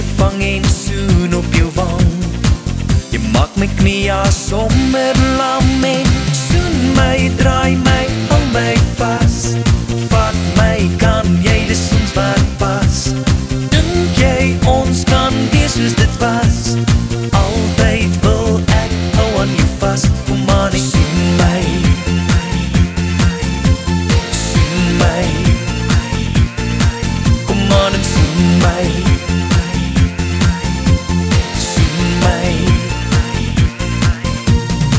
# Vocal